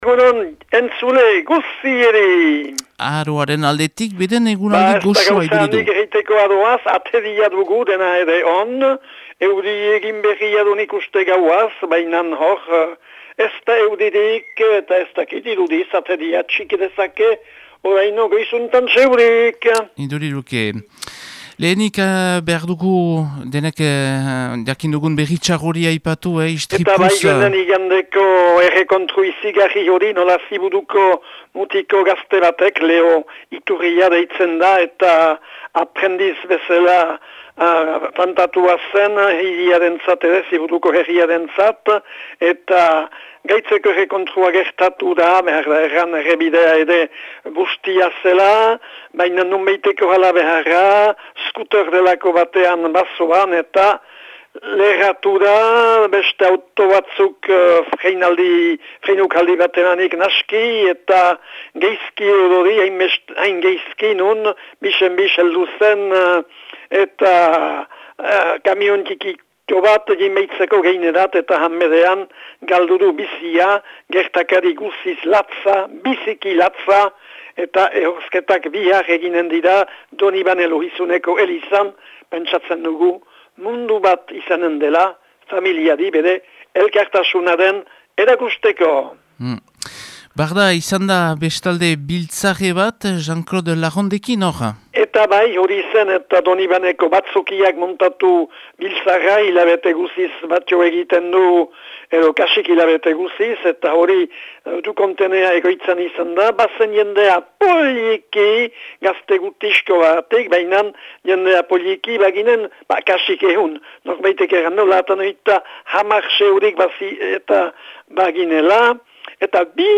laguntzailearen berriak